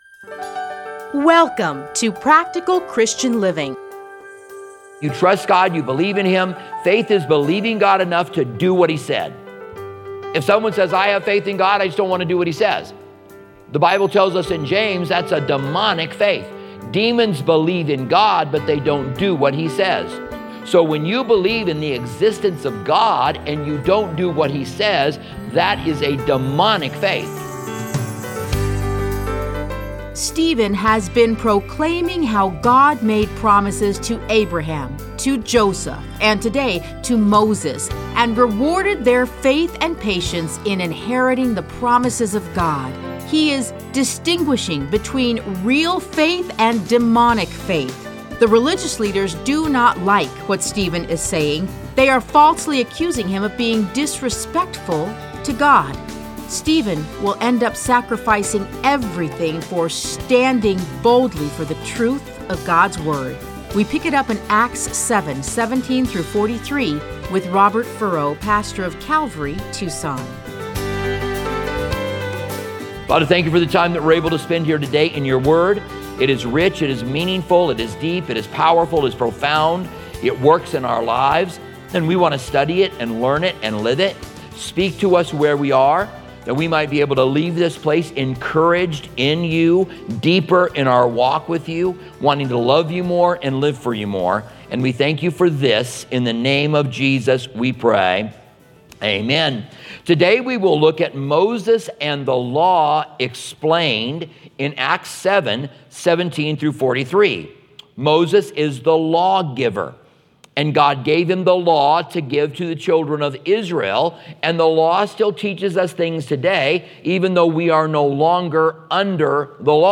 Listen to a teaching from Acts 7:17-43.